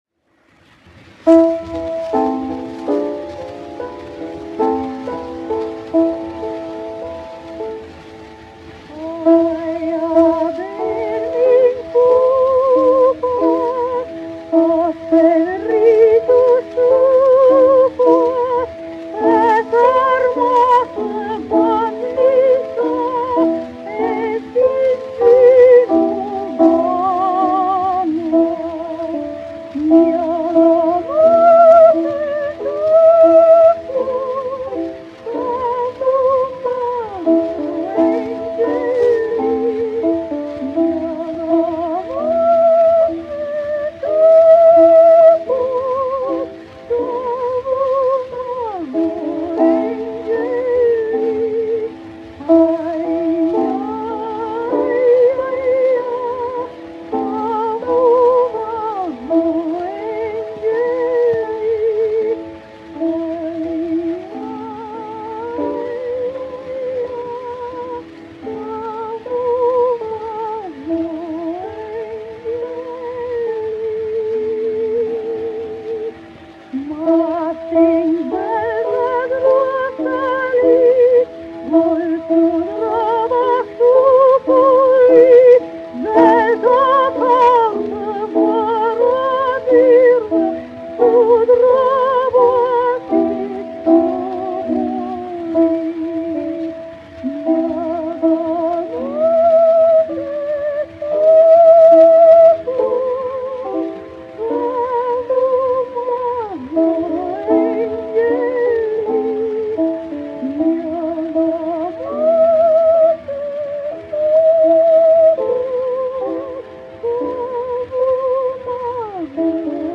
Aija, bērniņ, pūpās : tautas dziesma
Jāzeps Vītols, 1863-1948, aranžētājs
1 skpl. : analogs, 78 apgr/min, mono ; 25 cm
Latviešu tautasdziesmas
Skaņuplate